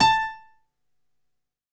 55ay-pno05-a#4.wav